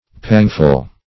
pangful - definition of pangful - synonyms, pronunciation, spelling from Free Dictionary Search Result for " pangful" : The Collaborative International Dictionary of English v.0.48: Pangful \Pang"ful\, a. Full of pangs.